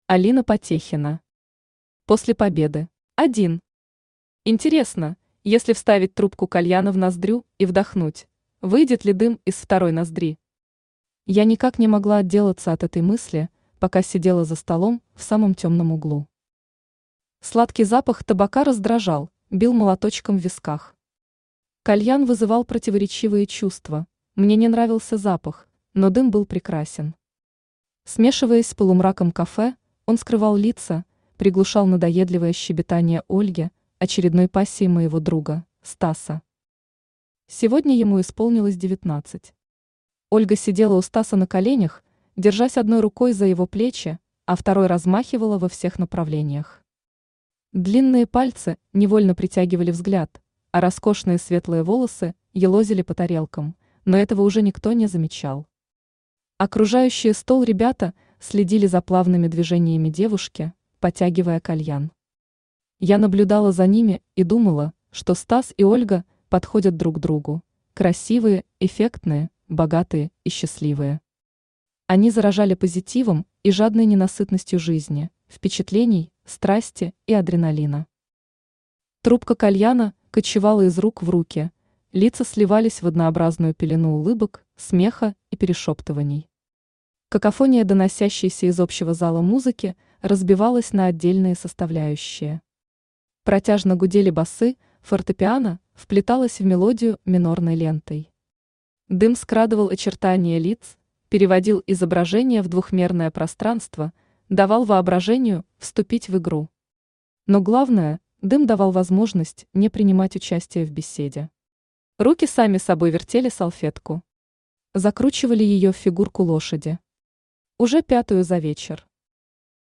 Aудиокнига После Победы Автор Алина Потехина Читает аудиокнигу Авточтец ЛитРес.